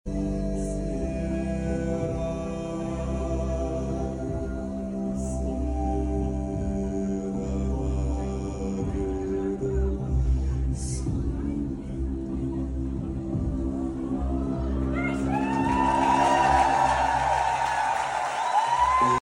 Das Bier davor hat geholfen 😜🤟 Kannst du auch tief singen?